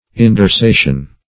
Indorsation \In`dor*sa"tion\, n.